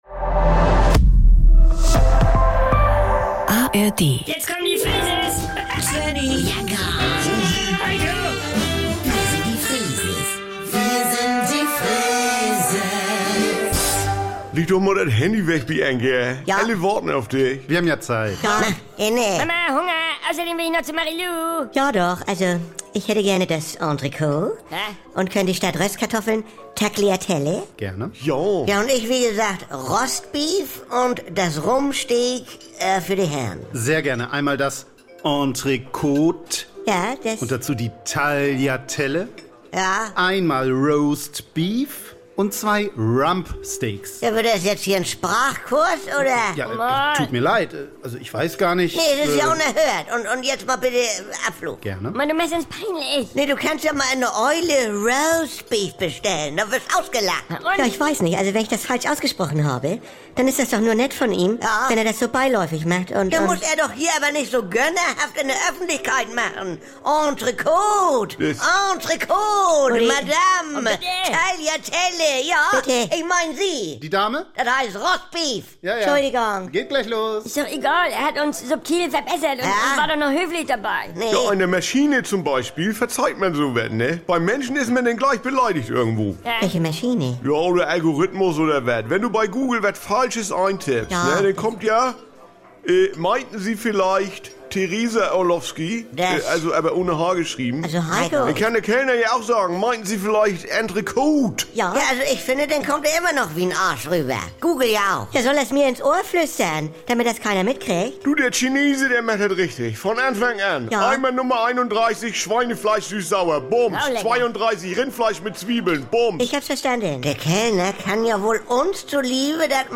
Jederzeit und so oft ihr wollt: Die NDR 2 Kult-Comedy direkt aus dem Mehrgenerationen-Haushalt der Familie Freese.